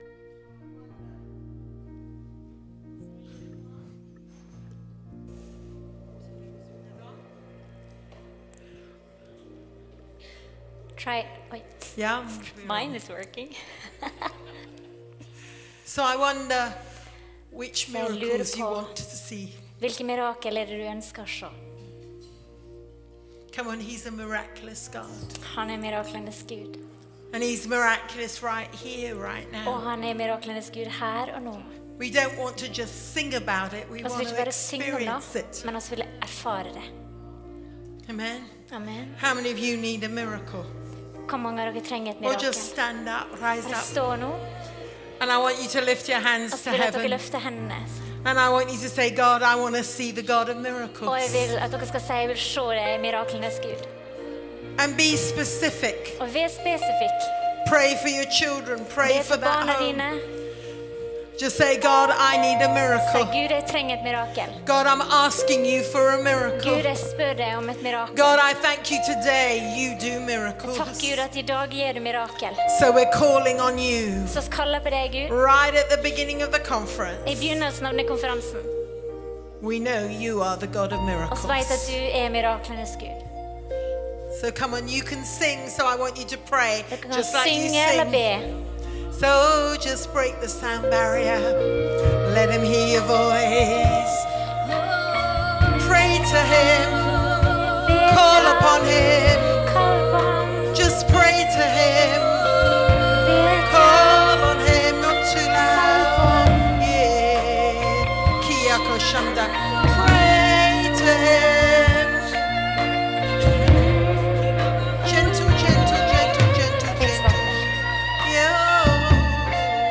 Tale